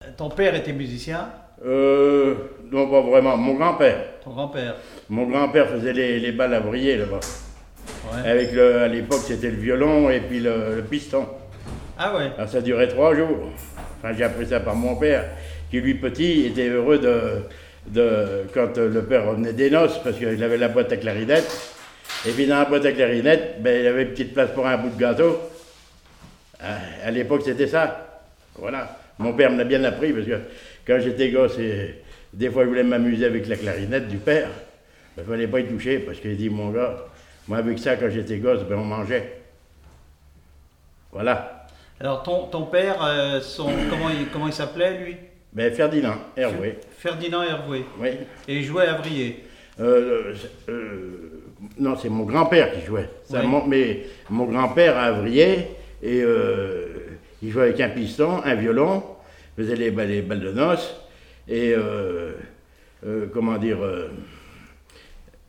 Témoignage sur la musique et des airs issus du Nouc'h
Catégorie Témoignage